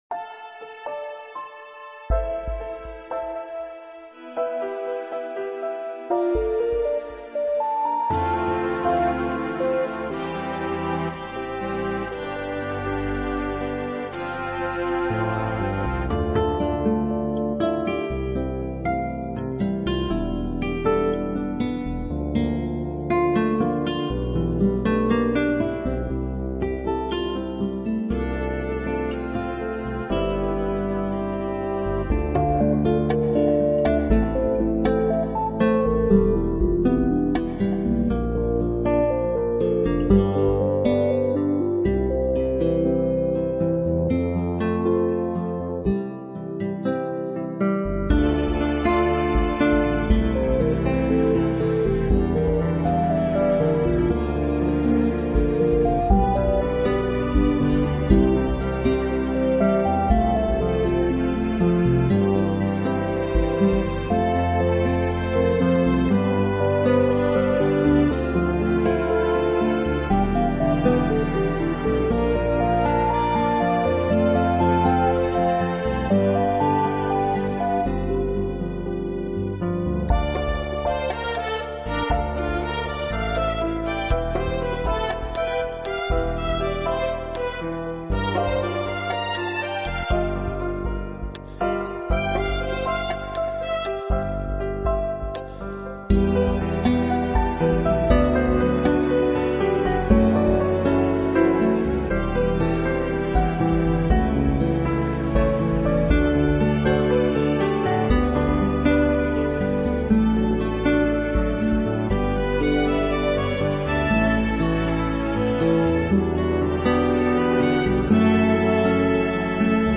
Relaxed Orchestral soundtrack Music